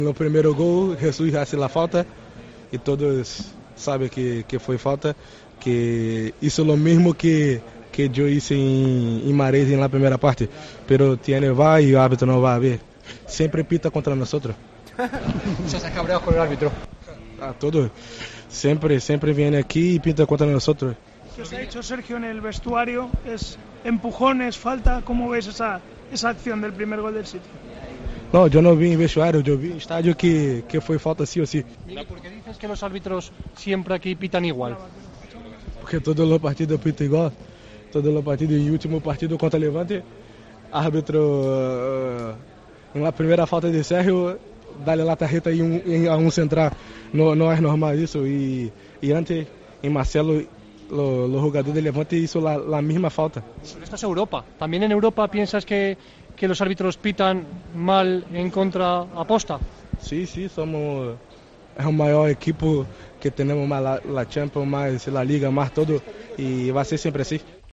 El brasileño Vinicius Junior se mostró especialmente crítico en zona mixta tras la derrota del Real Madrid contra el Manchester City (1-2), en la ida de octavos de final de la Liga de Campeones, con la actuación de los colegiados ya que piensa que “siempre pitan contra” ellos en referencia a la jugada de su compatriota Gabriel Jesús con el español Sergio Ramos en la jugada del empate.